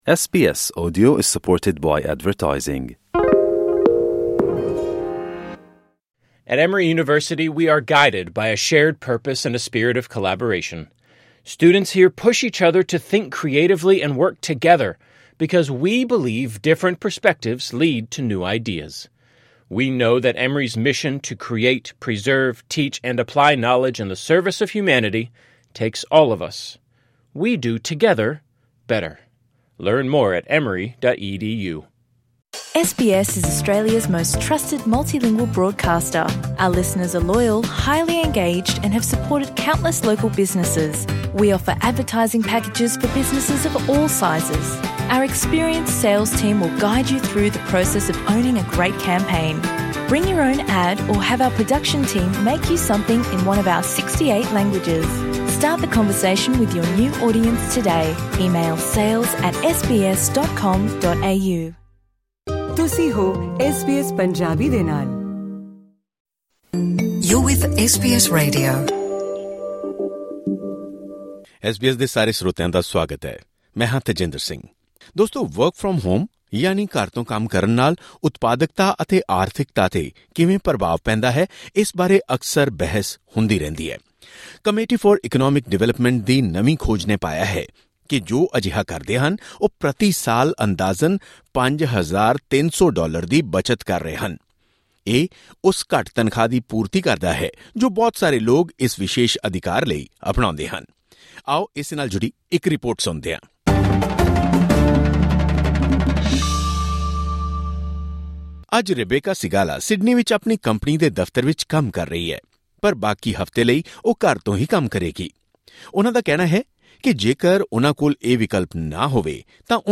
'ਕਮੇਟੀ ਆਫ ਇਕੋਨੌਮਿਲ ਡਿਵੈਲਪਮੈਂਟ' ਦੀ ਨਵੀਂ ਖੋਜ ਵਿੱਚ ਪਾਇਆ ਗਿਆ ਹੈ ਕਿ ਜੋ 'ਵਰਕ ਫਰਾਮ ਹੋਮ' ਕਰਦੇ ਹਨ ਉਹ ਸਾਲਾਨਾ $5,300 ਦੀ ਬਚਤ ਕਰ ਰਹੇ ਹਨ। ਹਾਲ ਹੀ ਵਿੱਚ ਆਸਟ੍ਰੇਲੀਆ ‘ਚ ਮੁਕੰਮਲ ਹੋਈਆਂ ਸੰਘੀ ਚੋਣਾਂ ਵਿੱਚ ਵੀ ਇਹ ਮੁੱਦਾ ਸਾਹਮਣੇ ਆਇਆ ਸੀ। ਇਸ ਬਾਰੇ ਇੱਕ ਖਾਸ ਰਿਪੋਰਟ ਇਸ ਪੌਡਕਾਸਟ ਰਾਹੀਂ ਸੁਣੋ।